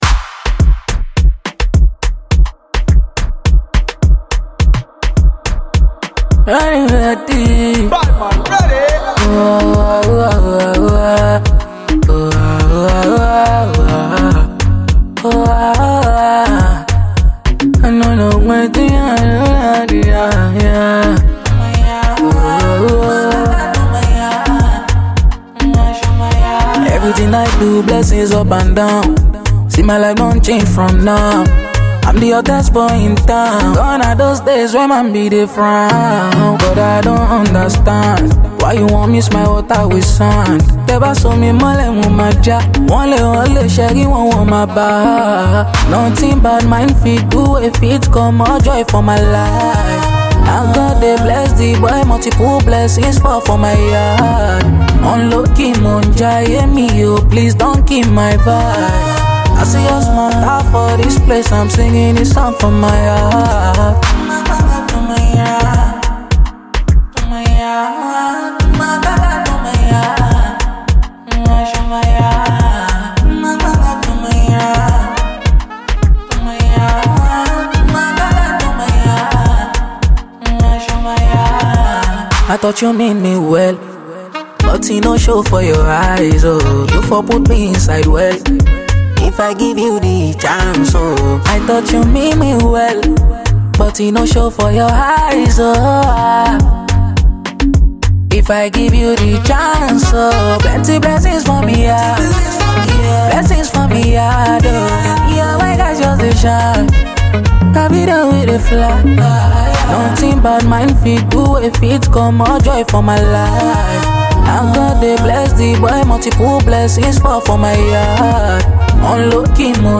Najia Afrobeat